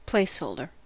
Ääntäminen
Vaihtoehtoiset kirjoitusmuodot place holder Synonyymit cadigan kadigan kadigin Ääntäminen US : IPA : [pleɪs.həʊl.də(r)] Haettu sana löytyi näillä lähdekielillä: englanti Käännöksiä ei löytynyt valitulle kohdekielelle.